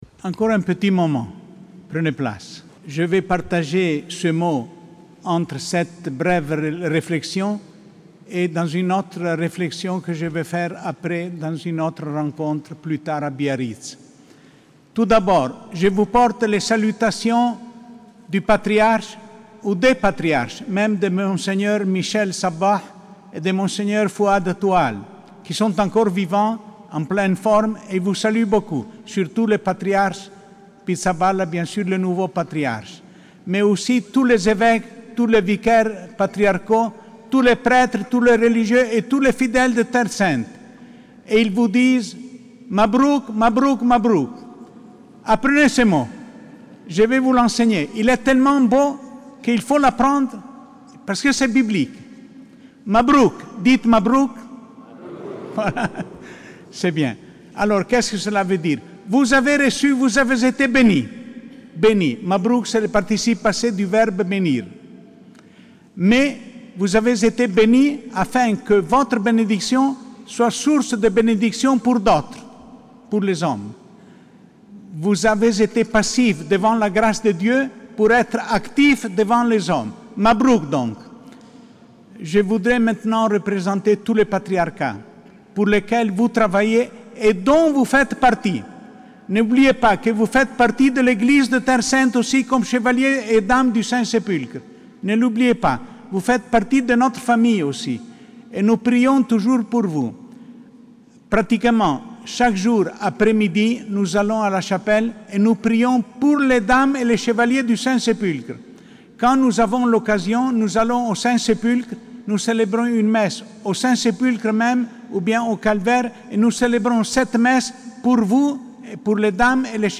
25 septembre 2021 – Messe des investitures de l’Ordre équestre du Saint-Sépulcre de Jérusalem - Intervention de Mgr Giacinto-Boulos Marcuzzo, vicaire émérite du Patriarcat latin de Jérusalem